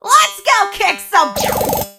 jackie_drill_start_vo_01.ogg